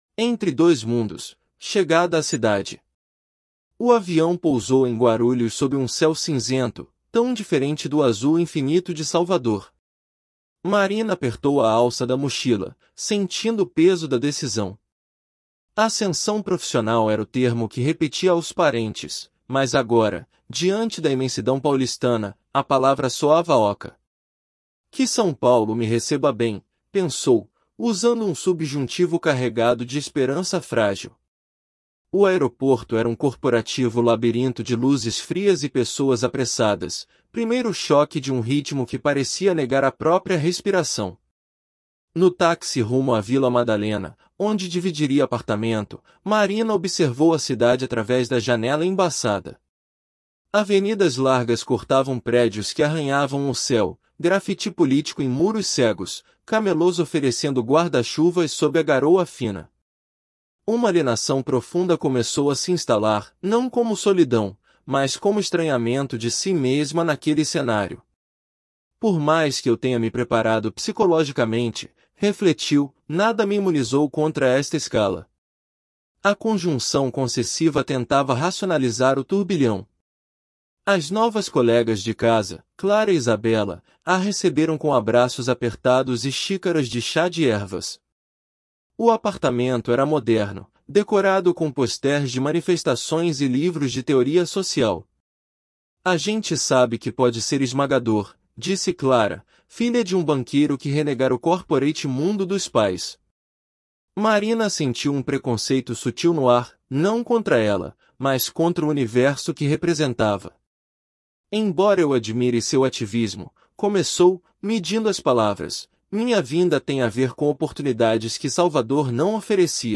• Before reading: Listen to understand rhythm, intonation, and natural speech.